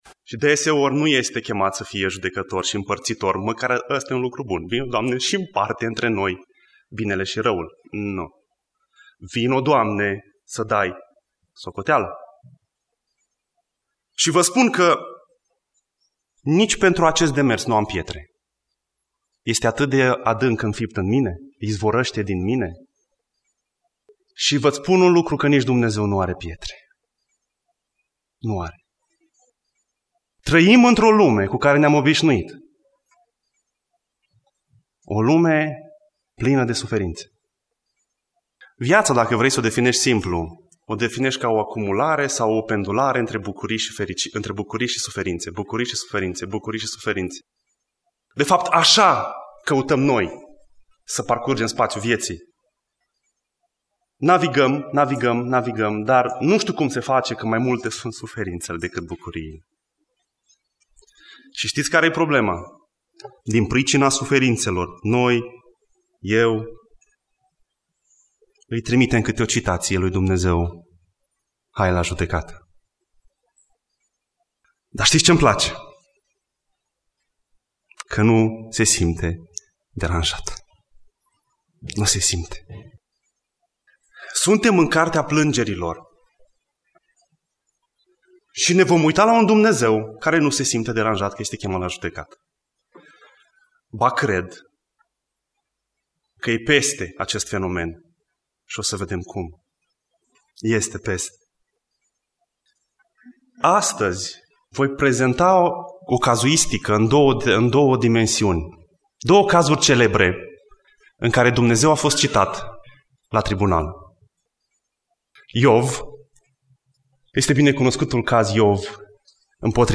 Predica Exegeza- Plangerile lui Ieremia 2